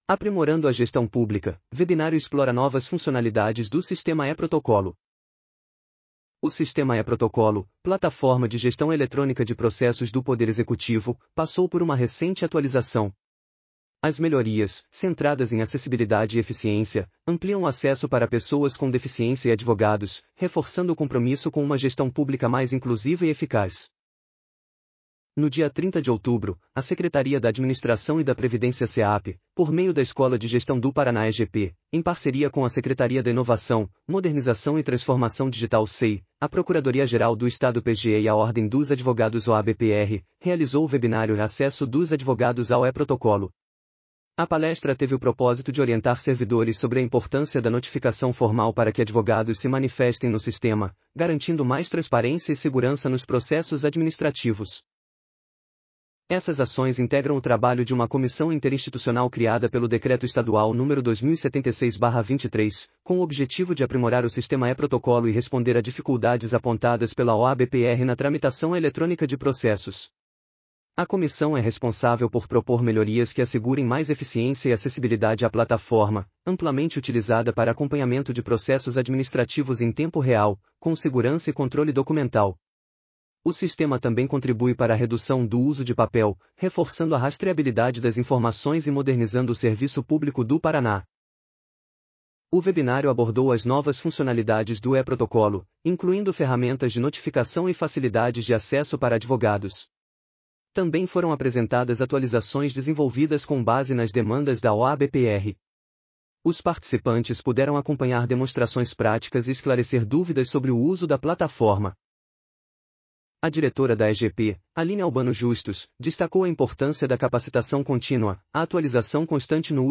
audionoticia_novas_funcionalidades_do_sistema_e-protocolo.mp3